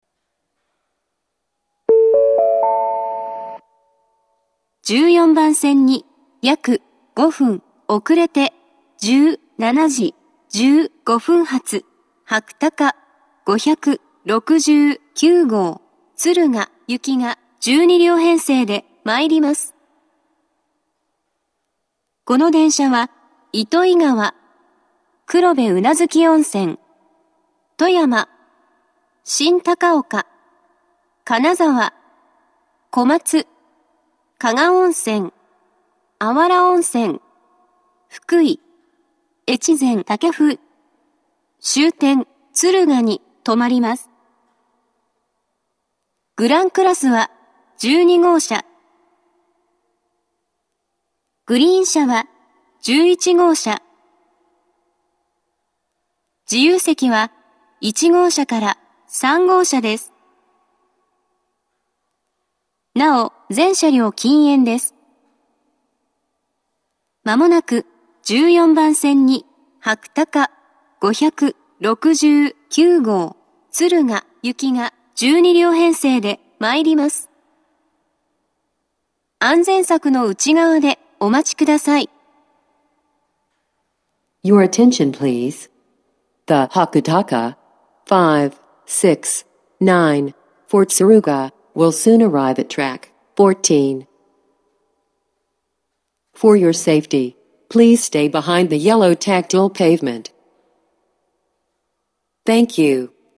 １４番線接近放送